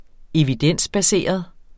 Udtale [ -baˌseˀʌð ]